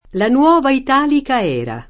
era [$ra] s. f. («epoca») — es. con acc. scr. (èra, non éraêra): La nuova italica èra [